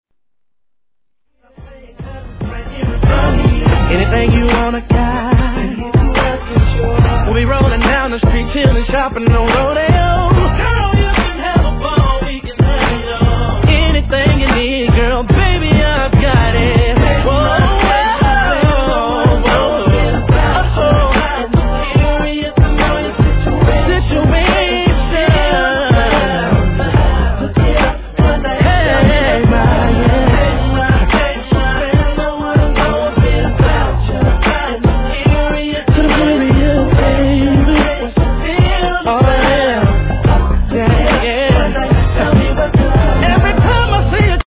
HIP HOP/R&B
72 BPM